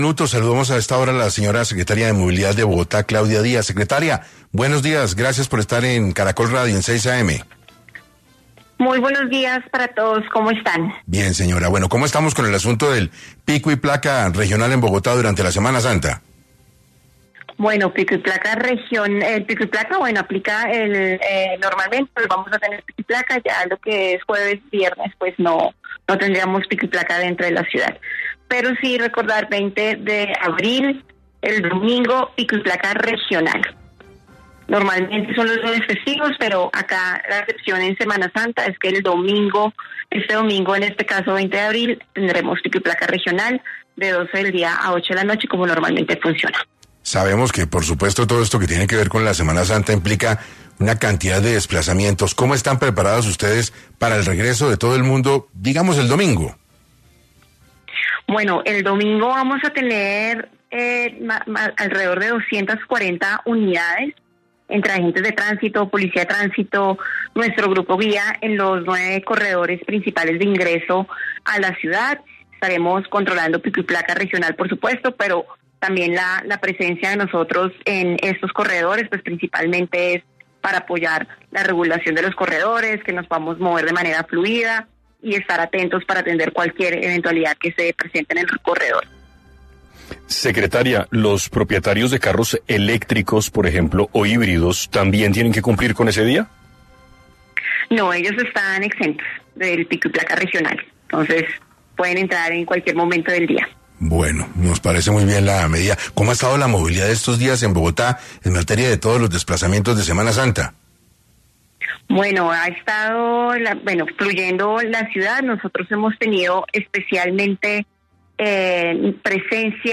En entrevista para 6AM,Claudia Díaz, Secretaria de Movilidad de Bogotá, explicó las nuevas medidas que tendrá el pico y placa para la Semana Santa.